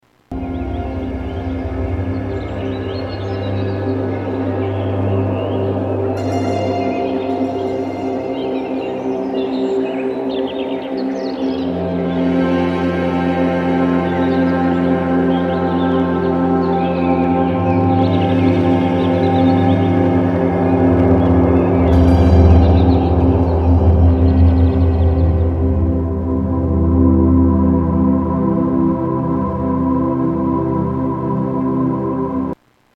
Tags: Media Hypnosis Self Hypnosis Relaxation Sounds Brainwave Sounds